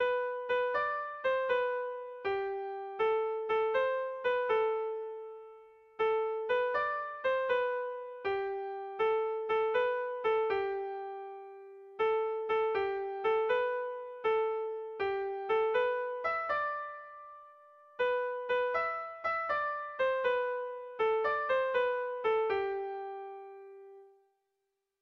Zortziko berezia, 4 puntuz (hg) / Lau puntuko berezia (ip)
A1A2BD